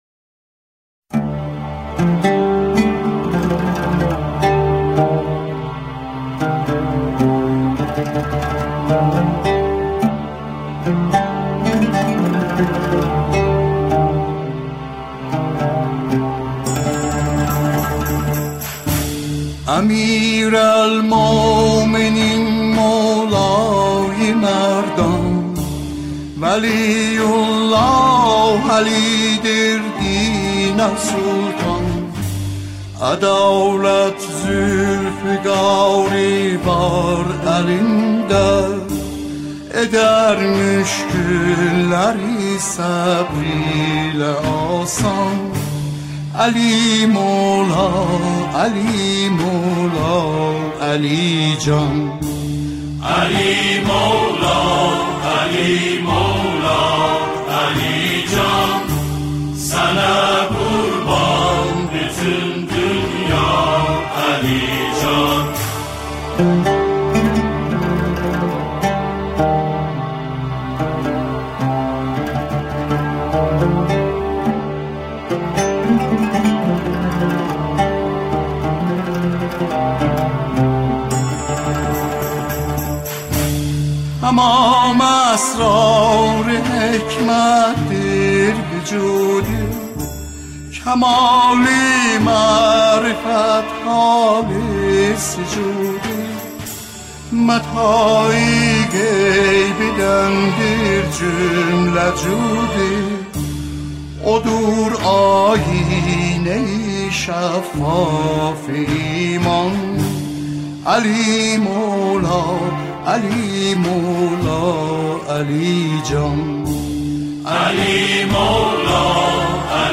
همراهی گروه کر